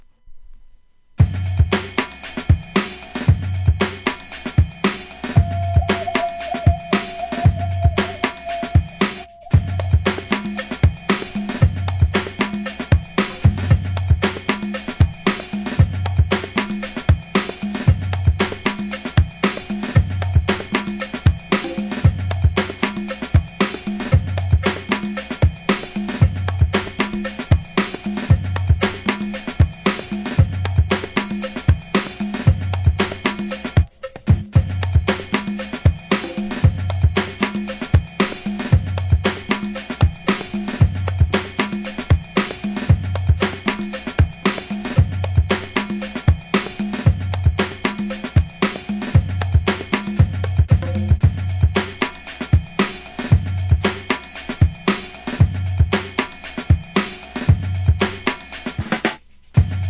HIP HOP/R&B
全20 BEATS & GROOVES!!